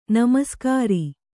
♪ namaskāri